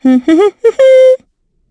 Estelle-Vox_Hum_b.wav